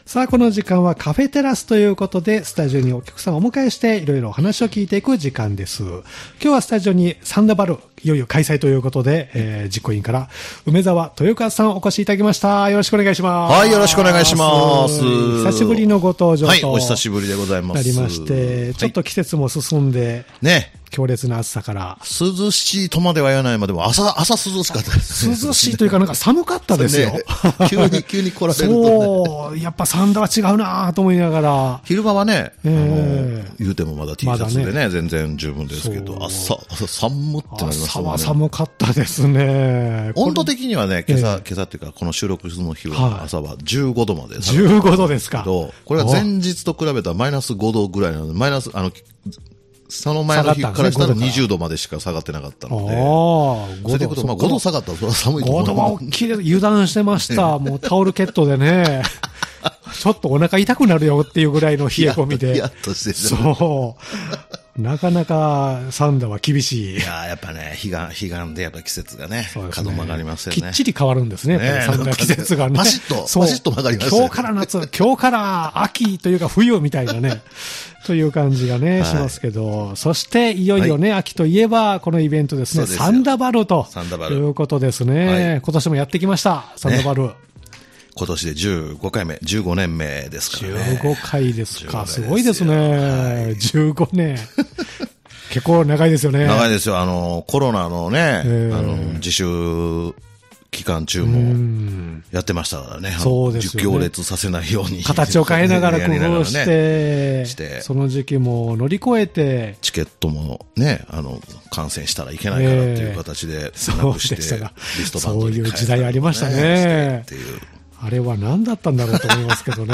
様々なジャンルで活動・活躍されている方をお迎えしてお話をお聞きするポッドキャスト番組「カフェテラス」（再生ボタン▶を押すと放送が始まります）